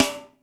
HOUSE SNARE.wav